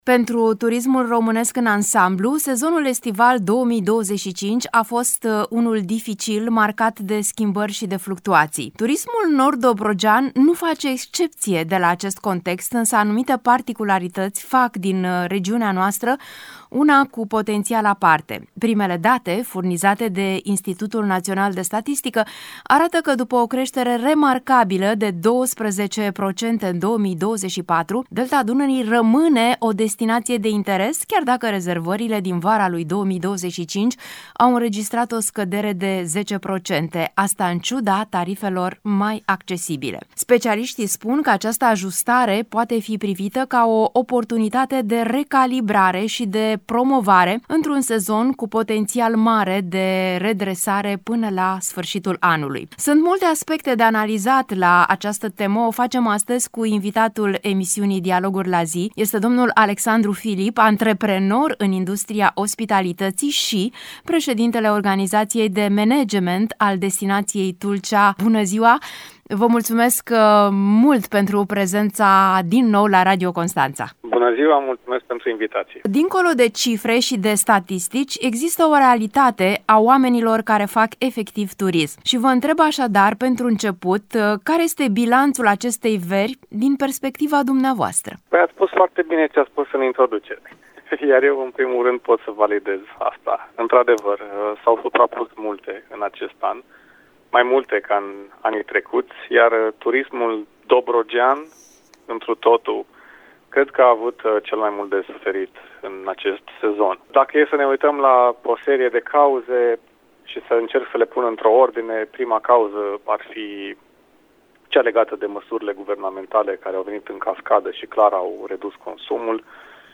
Emisiunea “Dialoguri la zi” poate fi ascultată aici: Share pe Facebook Share pe Whatsapp Share pe X